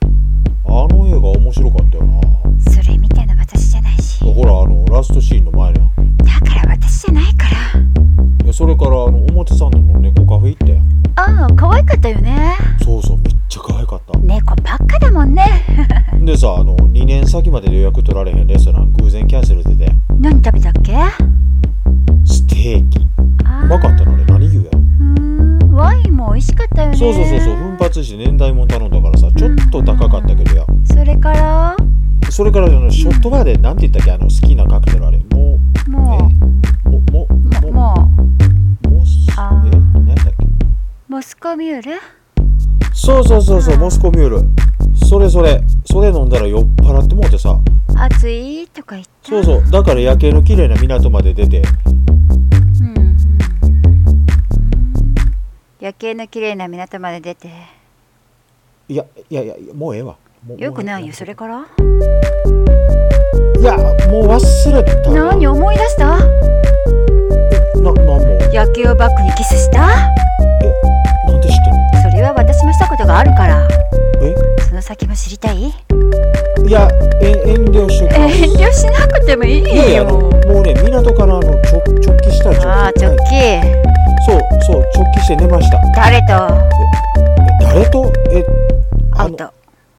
【声劇】アウト